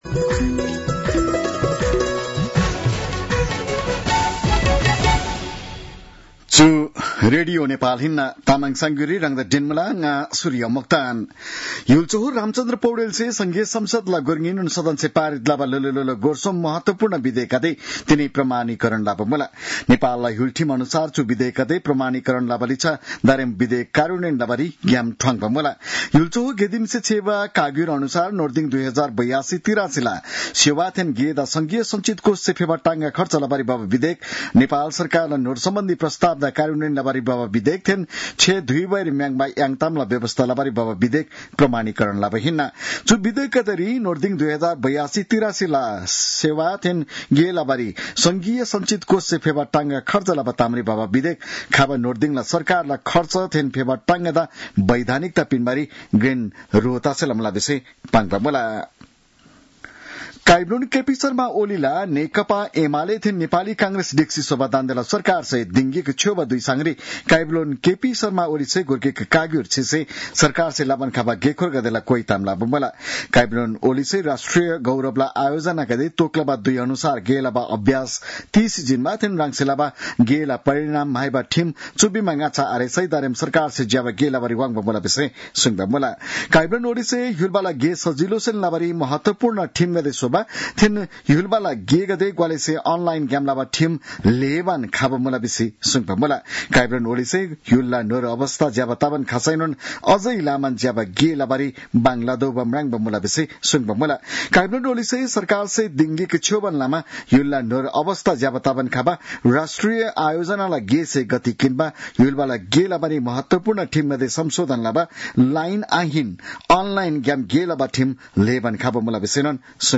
तामाङ भाषाको समाचार : ३० असार , २०८२